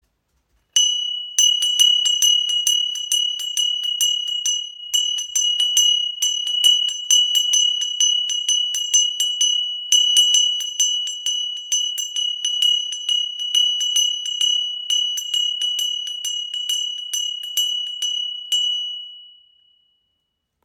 Tempelglocke mit Hanuman · Traditionelles Ritualinstrument · 9 cm
• Icon Reiner Klang – Ideal für Rituale und spirituelle Praktiken
Die Tempelglocke mit Hanuman erzeugt einen klaren Klang und symbolisiert Stärke, Hingabe und die Überwindung von Hindernissen.
• Material: Messing